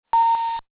electronicpingshort.mp3